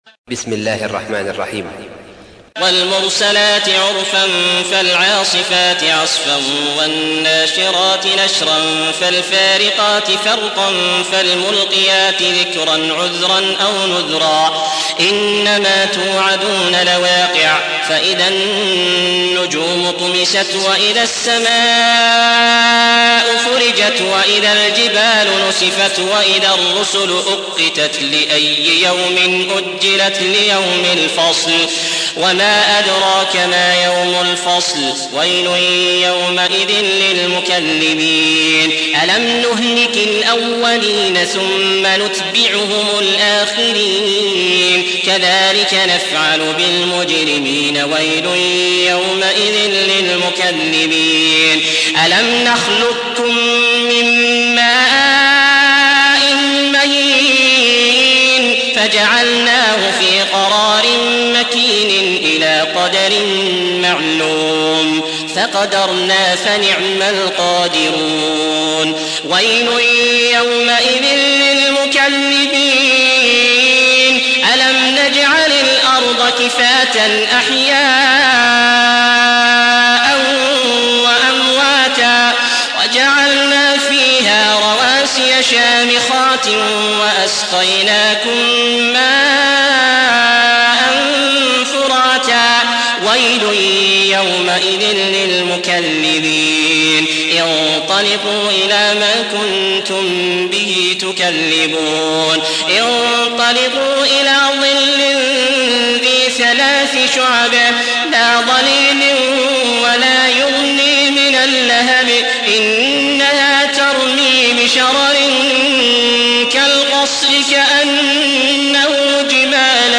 77. سورة المرسلات / القارئ